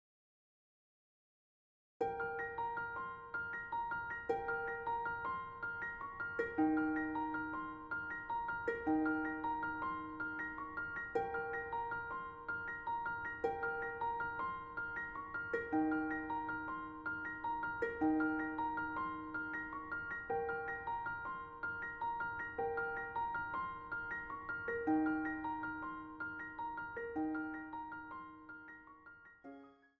Backing